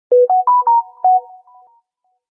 알림음 Samsung